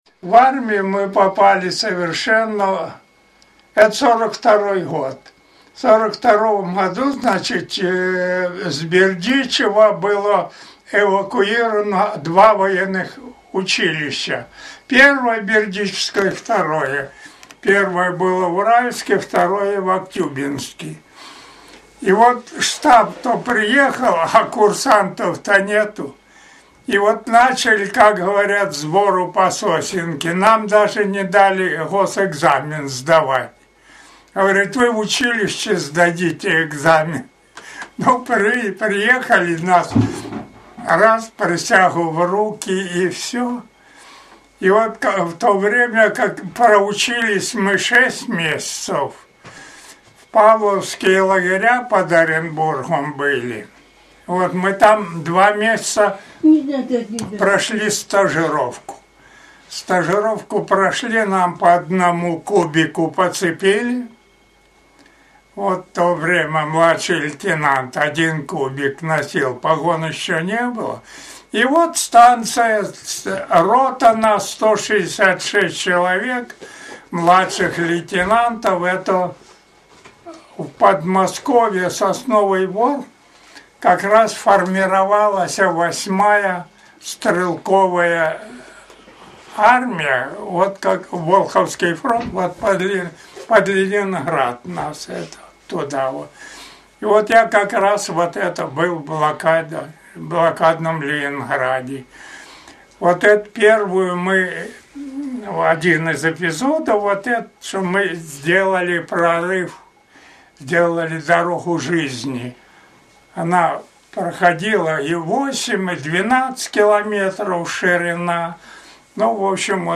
Интервью состоит из небольшого аудиофайла с рассказом о всех тягостях и радостях участника войны…